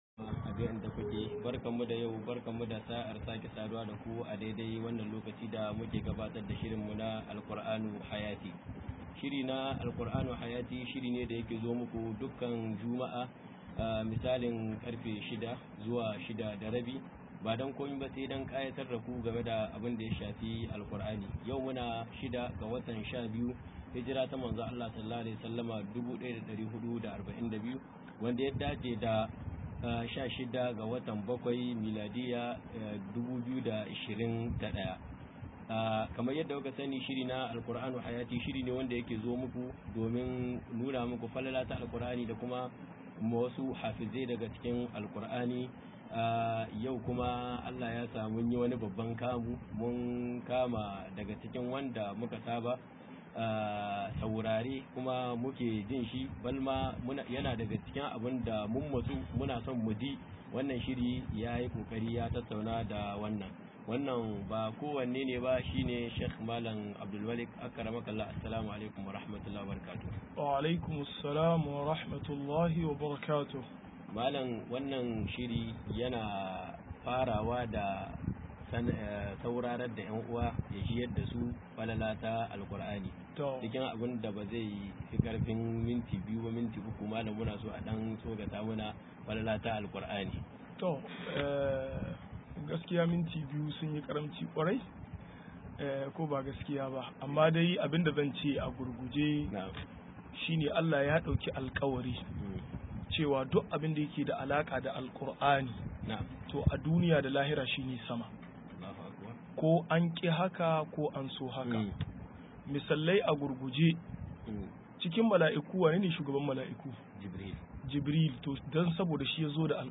155-Falal Alkuraani Radio Alumma Konni - MUHADARA